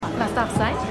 ForAllMankind_5x04_Barfrau.mp3